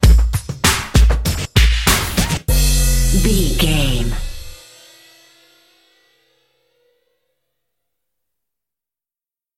Ionian/Major
drum machine
synthesiser
hip hop
Funk
neo soul
acid jazz
confident
energetic
bouncy
funky